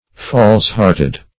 False-hearted \False"-heart`ed\, a.